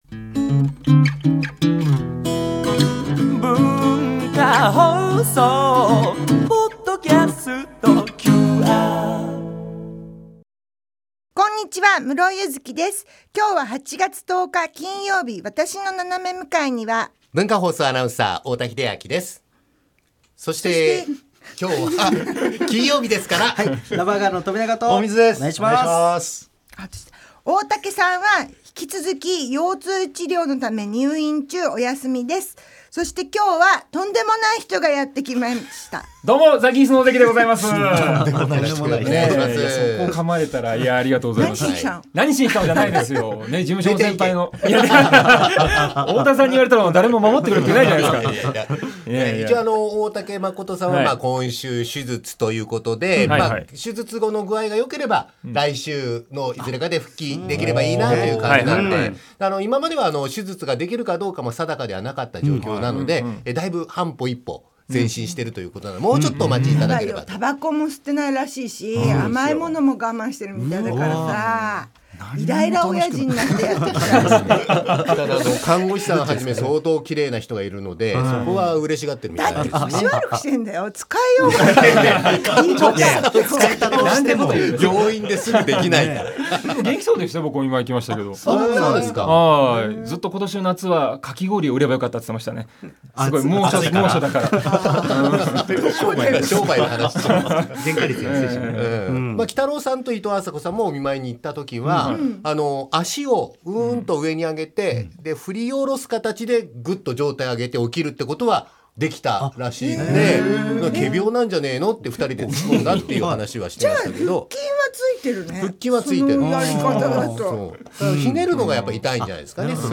大竹まことさんとパートナーとのオープニングトークをお楽しみください！！